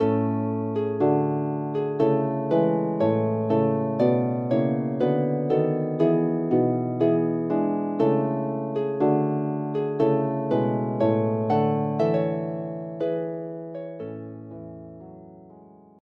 arranged for solo lever and pedal harp